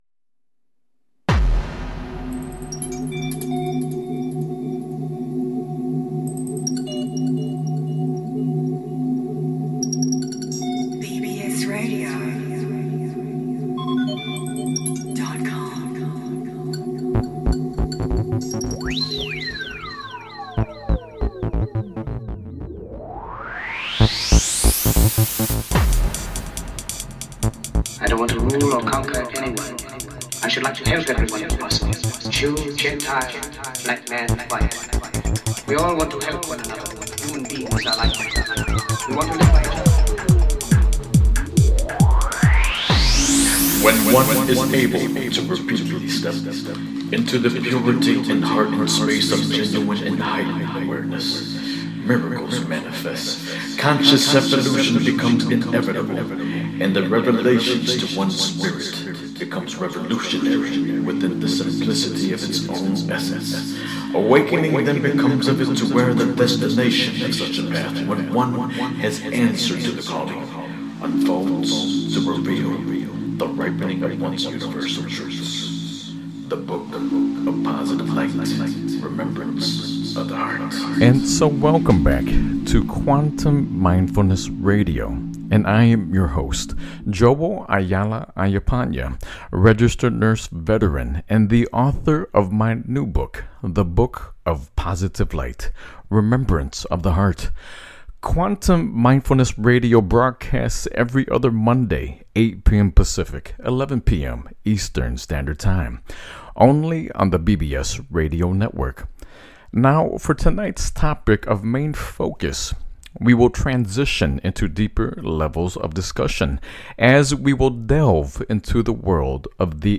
Headlined Show, Quantum Mindfulness Radio October 13, 2014